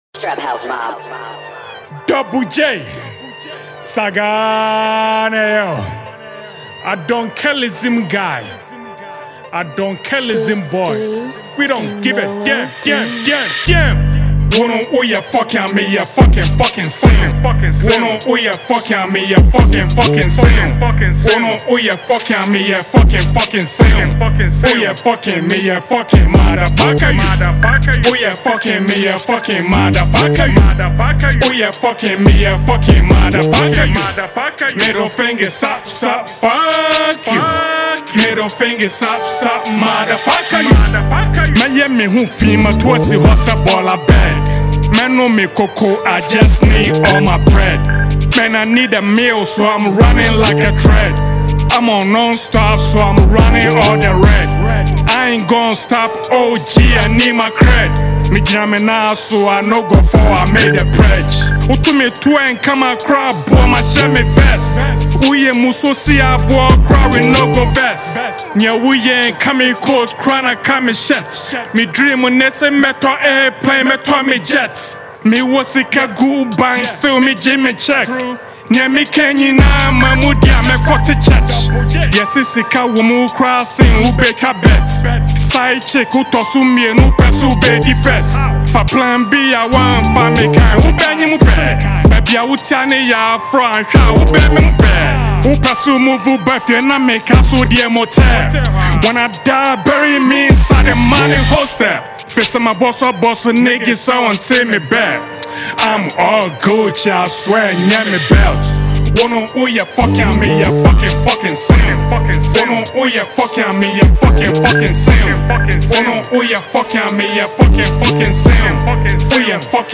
Ghana Music Listen
Ghanaian multi-talented rapper and songwriter